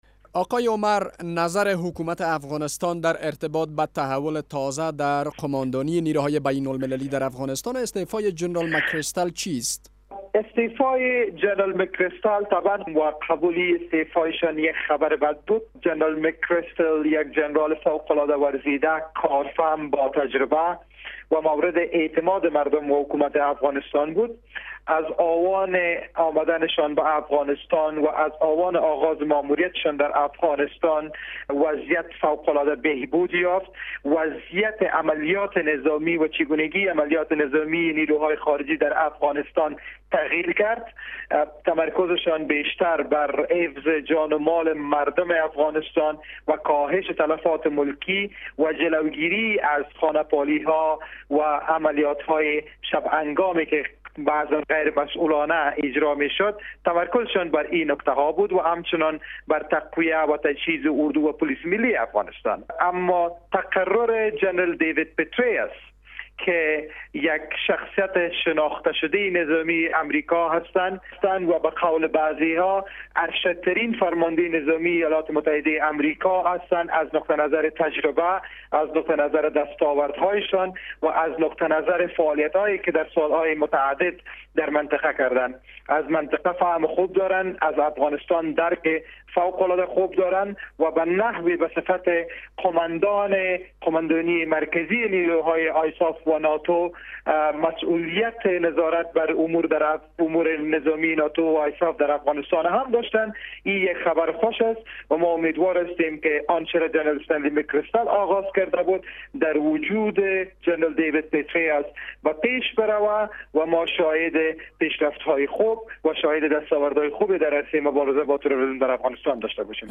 مصاحبه با وحید عمر در مورد تقرر دیوید پتریوس به حیث قوماندان عمومی نیروهای امریکایی و ناتو